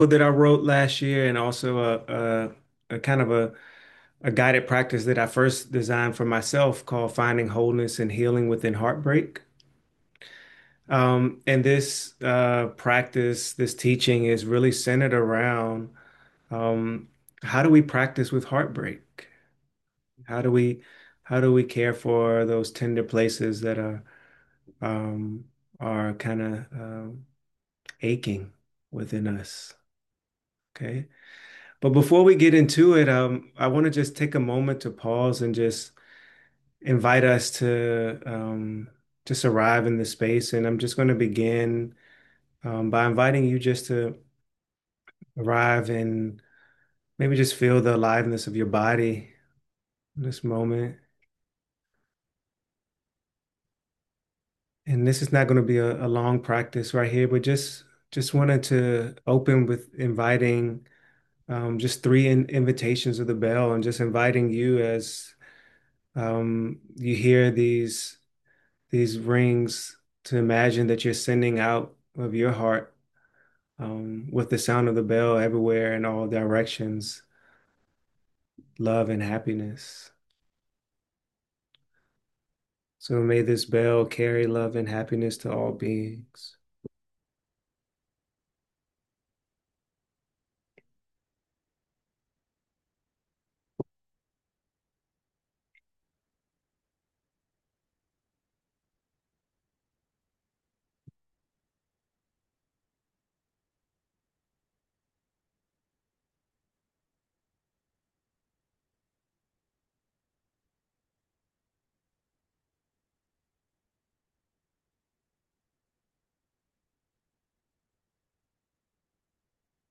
with guided visualization and contemplative work